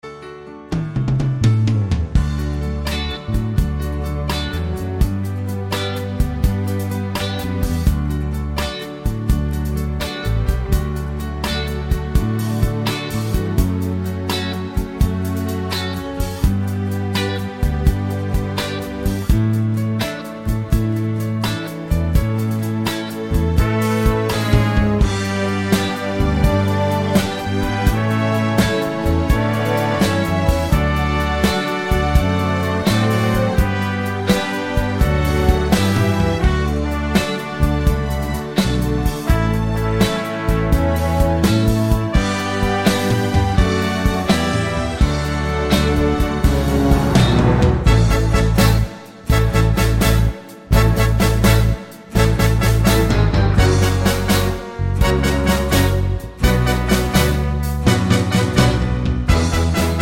no Backing Vocals Crooners 3:05 Buy £1.50